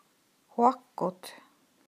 Hør hvordan ordet uttales: